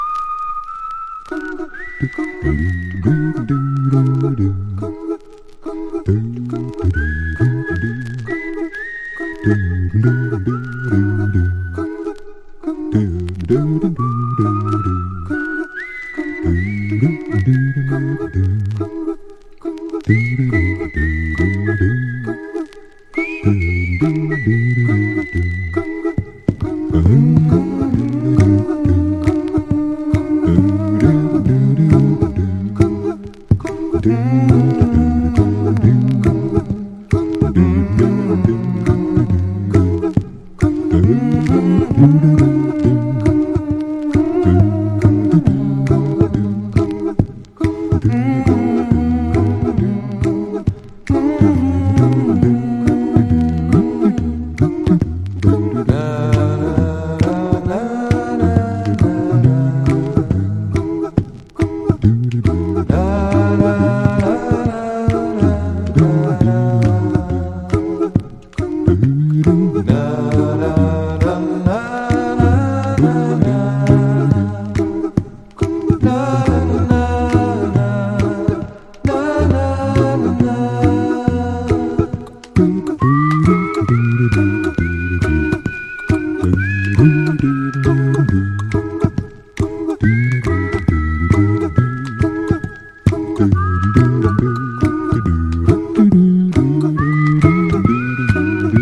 RECORDED AT – MAGIC SOUND STUDIO, MÜNCHEN